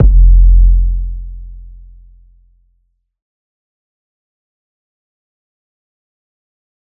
BOOMIN_ 808.wav